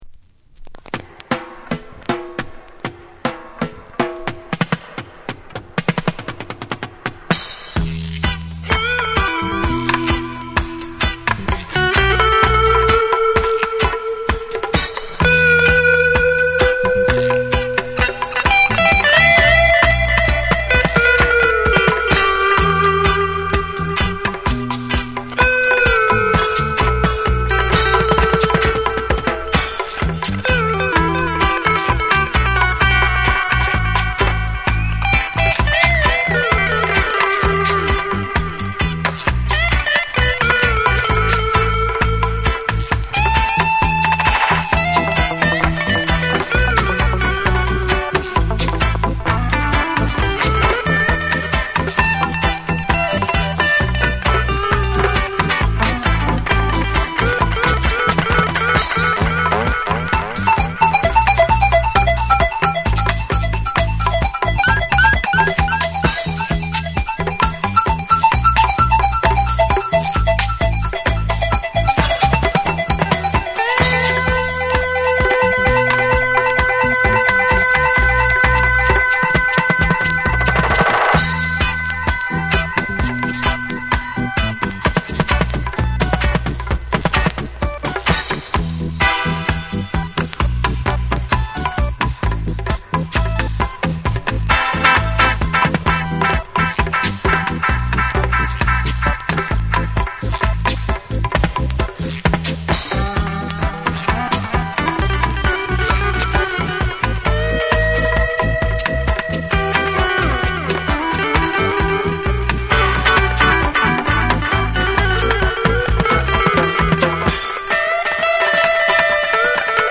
タイトなリディムにエコーやリバーブを効かせた空間処理が冴え渡る。
REGGAE & DUB